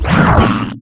alianhit3.ogg